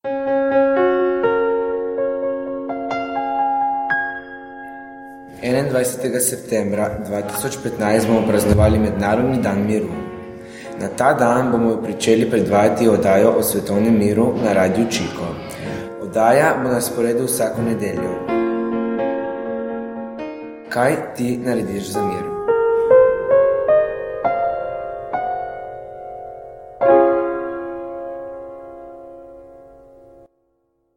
Jingle Weltfriedenswoche Slowenisch
Jingle Weltfrieden Slowenien.mp3